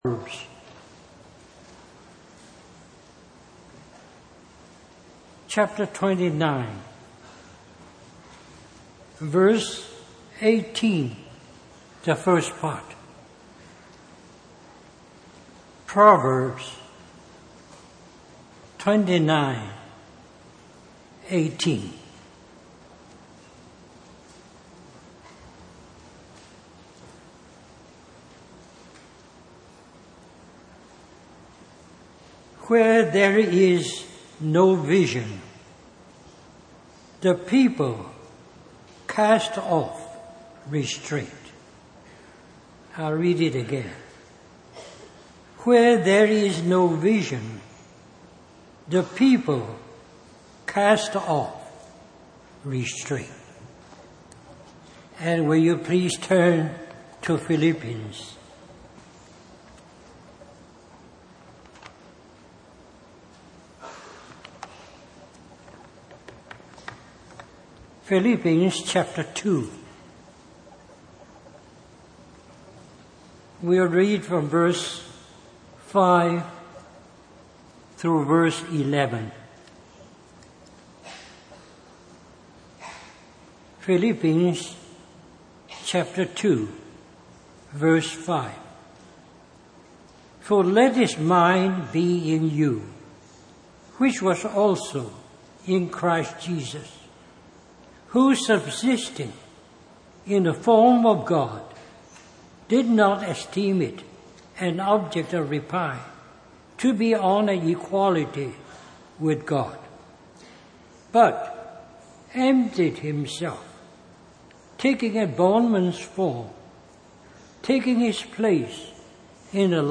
In this sermon, the preacher emphasizes the importance of having a vision in our lives.